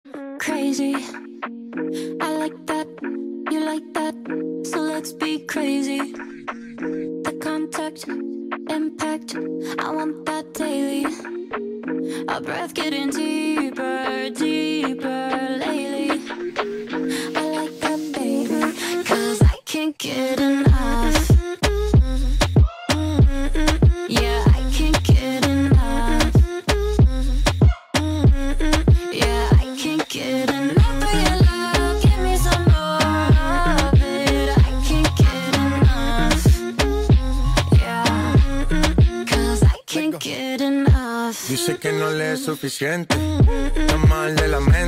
Kategori POP